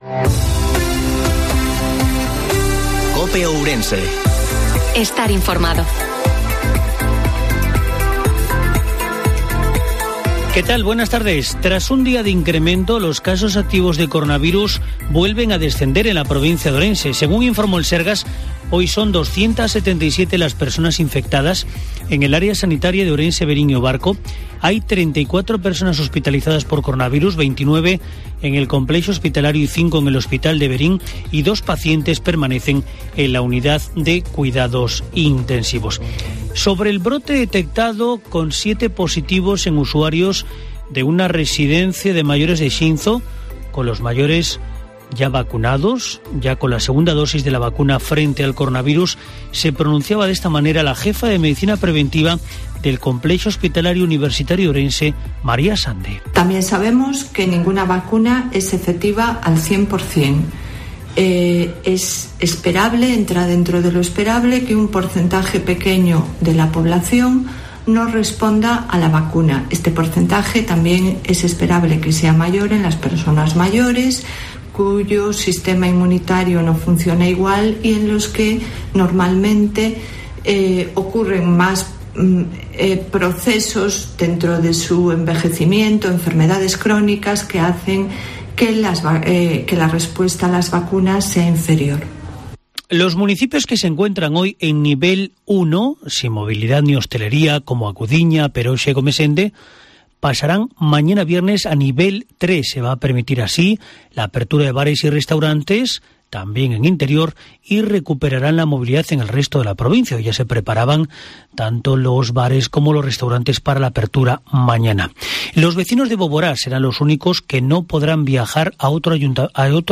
IFORMATIVO MEDIODIA COPE OURENSE